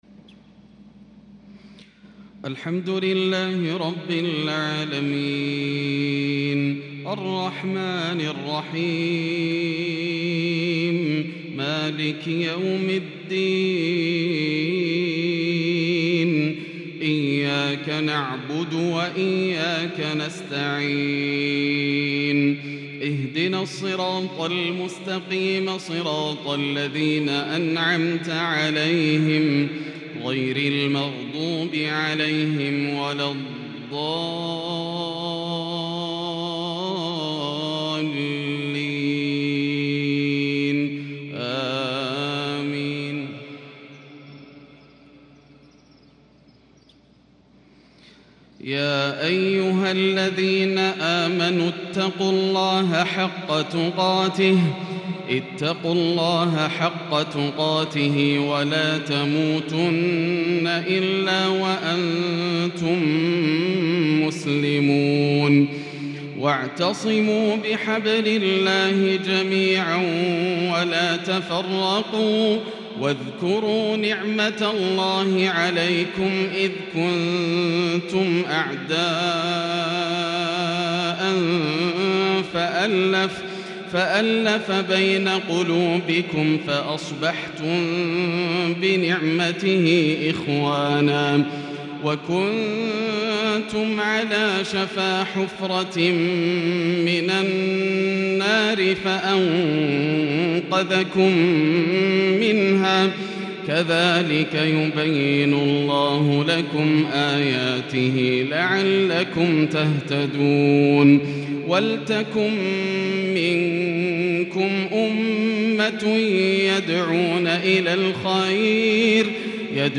مغرب الخميس 21 ذو القعدة 1442ھ من سورة آل عمران | Maghrib Prayer from Surat Al Imran | 1-7-2021 > 1442 🕋 > الفروض - تلاوات الحرمين